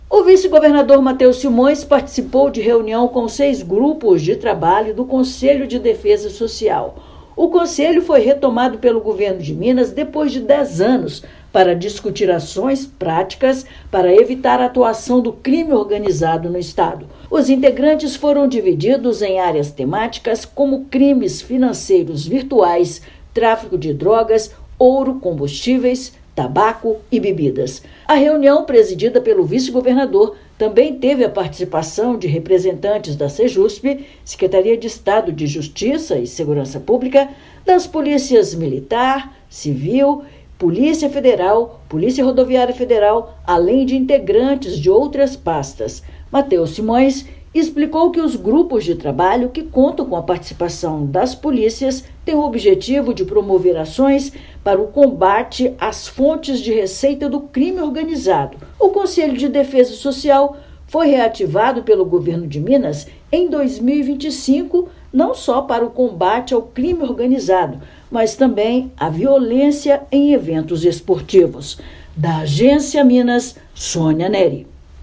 Medida é uma das diretrizes do conselho, reativado neste ano; integrantes foram divididos em seis grupos de trabalho. Ouça matéria de rádio.